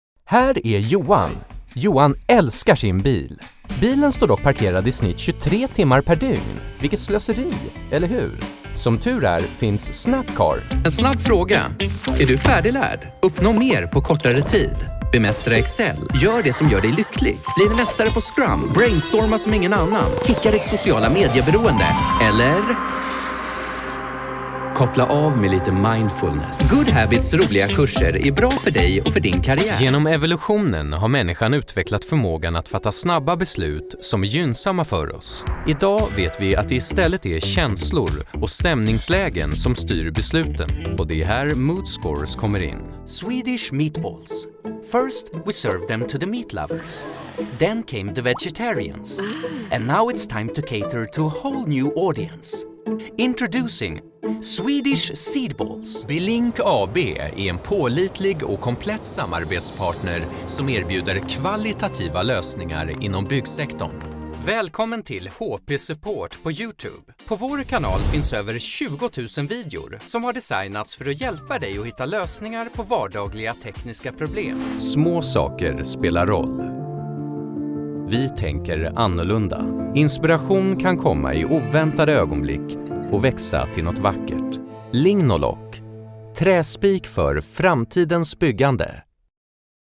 Male
Authoritative, Confident, Corporate, Engaging, Friendly, Warm, Young, Conversational, Energetic, Upbeat
Microphone: Neumann TLM-102
Audio equipment: Professional Sound Booth from Demvox, ECO100